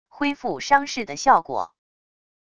恢复伤势的效果wav音频